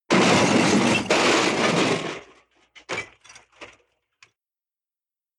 PLAY Pans Falling 3
pans-falling.mp3